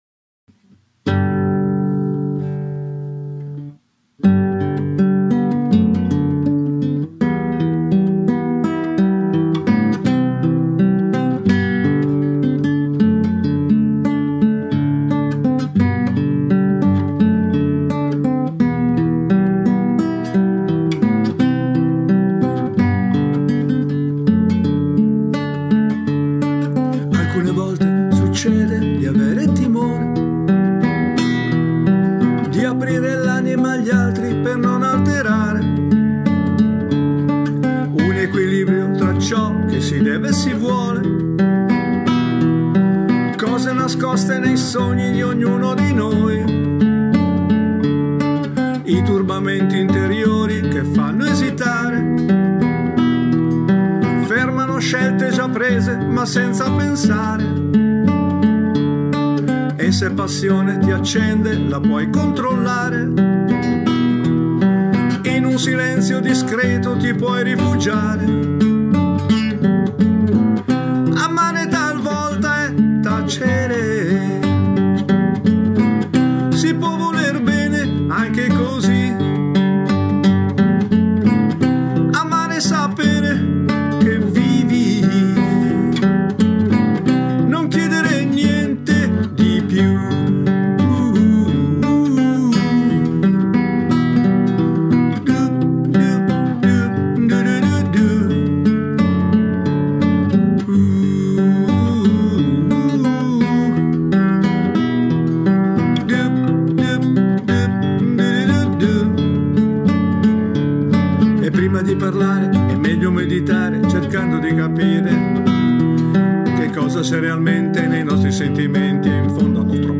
chitarra e canto